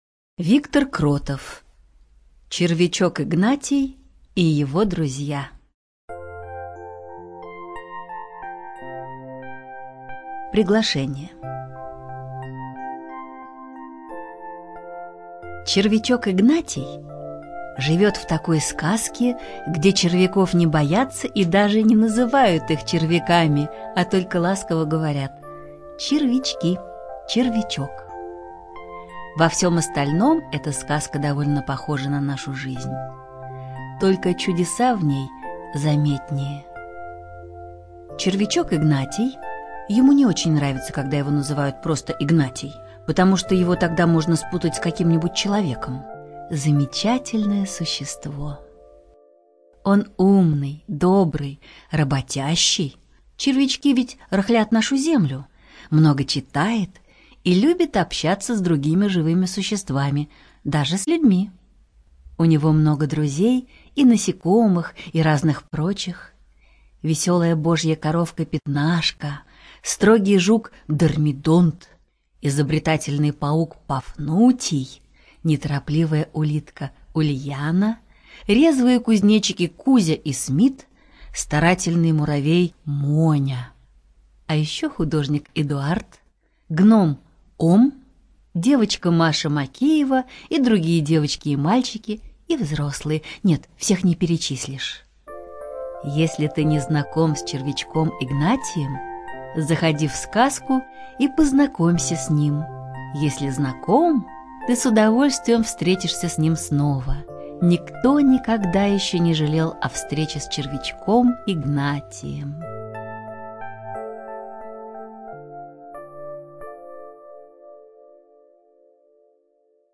ЧитаетВарлей Н.